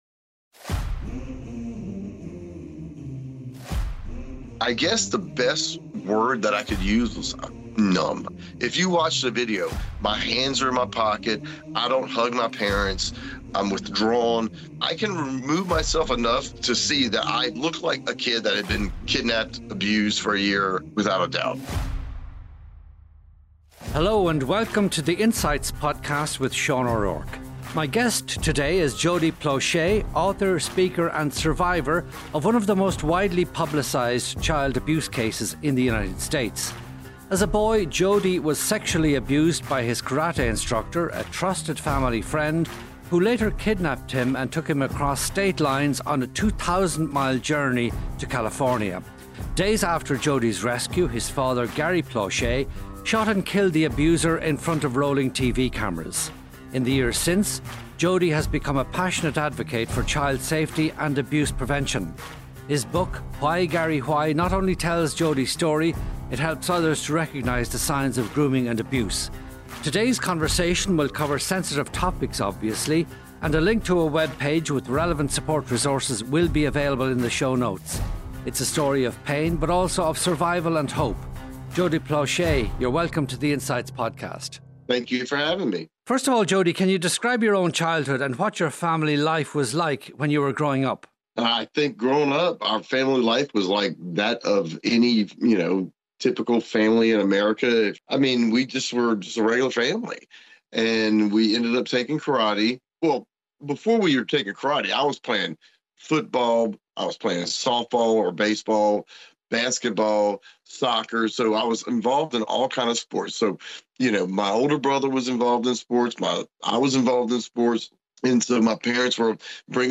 One-to-one conversations between Sean O'Rourke and well-known individuals. Featured guests are prominent in public service, culture, sport and business and these long-form conversations explore their motivations, achievements and regrets; in short, what made them the people they are.